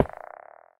Minecraft Version Minecraft Version 1.21.5 Latest Release | Latest Snapshot 1.21.5 / assets / minecraft / sounds / block / lodestone / place2.ogg Compare With Compare With Latest Release | Latest Snapshot